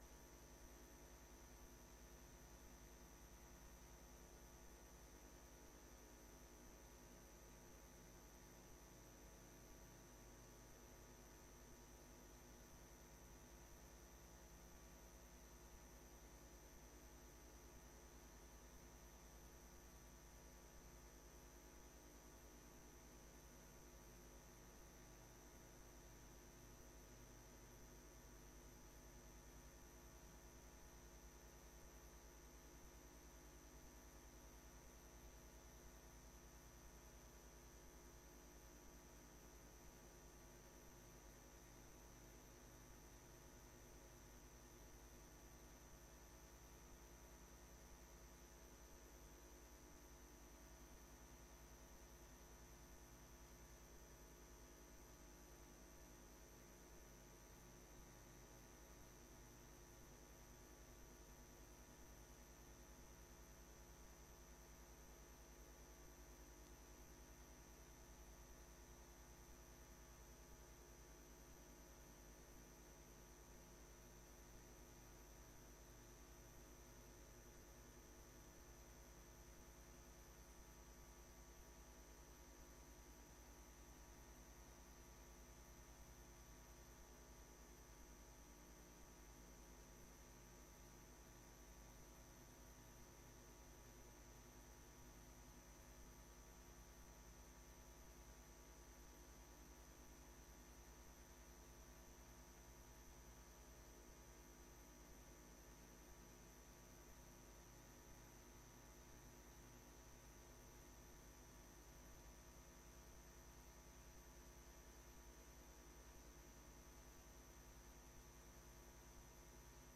beeldvormende raad 18 september 2025 19:30:00, Gemeente Doetinchem
Download de volledige audio van deze vergadering